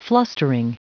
Prononciation du mot flustering en anglais (fichier audio)
Prononciation du mot : flustering